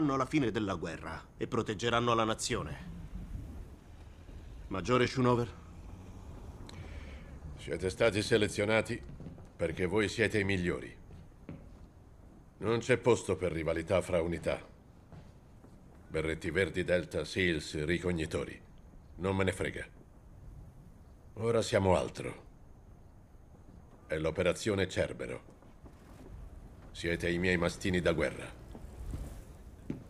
Massimo Lopez nel telefilm "The Punisher", in cui doppia Clancy Brown.